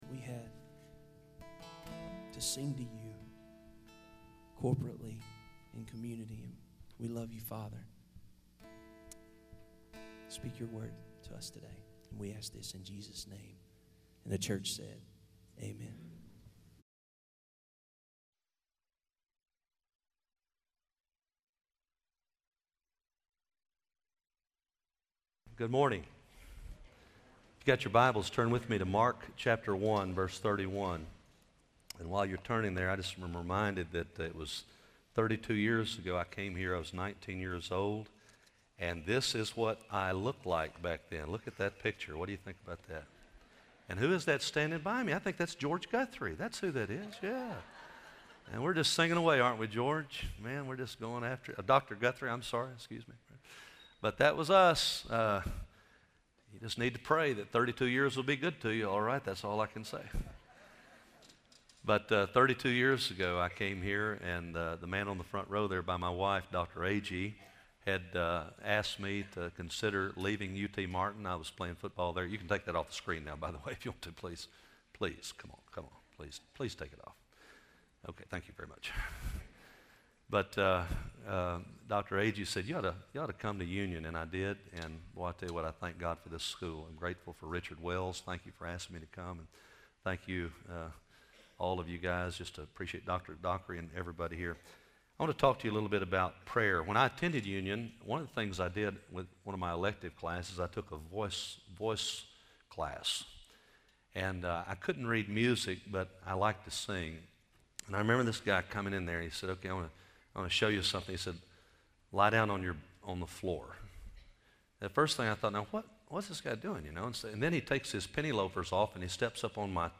Union University, a Christian College in Tennessee